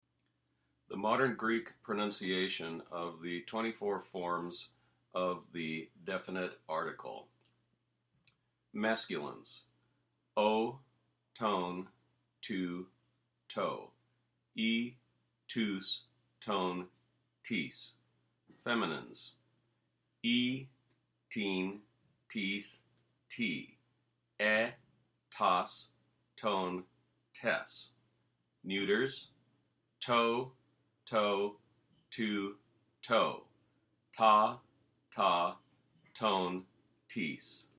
Pronunciation Of The 24 Definite Articles
This audio clip provides the correct Modern pronunciation of the Articles.
Greek Definite Articles.mp3